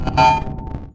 描述：我所创造的一些突变/环境声音。
标签： 电子 毛刺 IDM Reaktor的
声道立体声